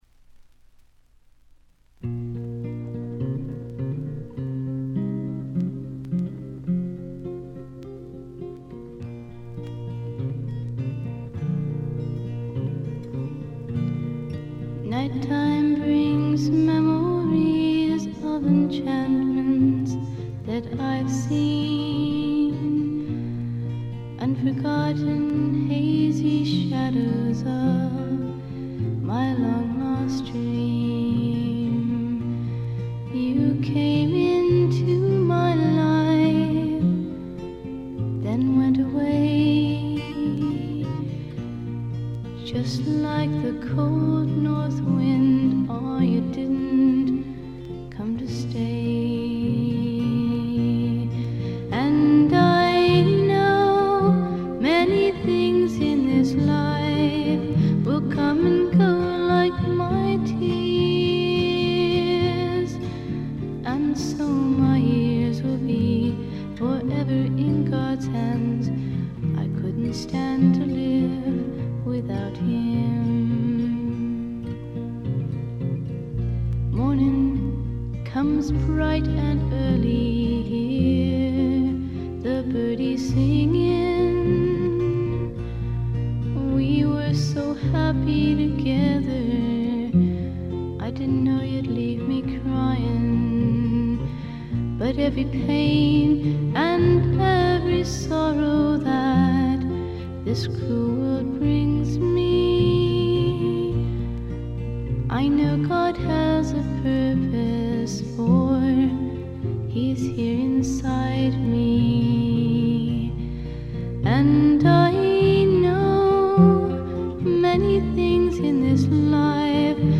ところどころで軽微なチリプチ。気になるようなノイズはありません。
演奏はほとんどがギターの弾き語りです。
試聴曲は現品からの取り込み音源です。